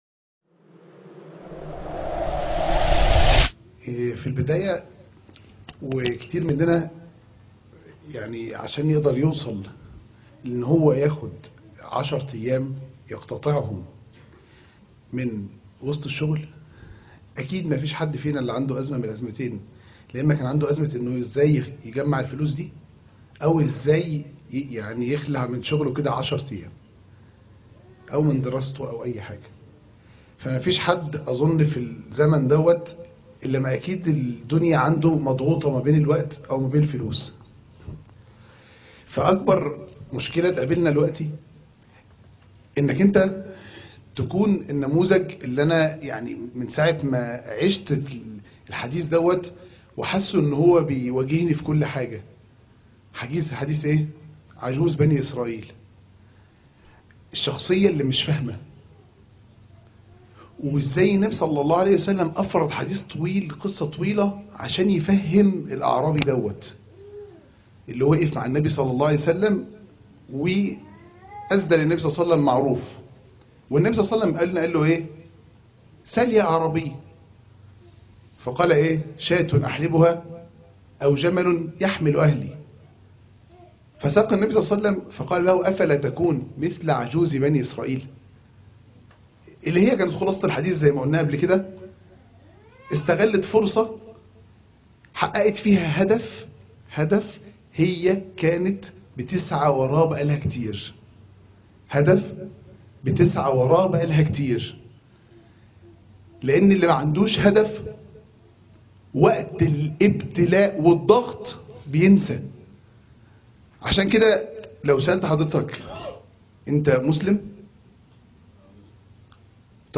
هدفك إيه من العمرة ؟- الدرس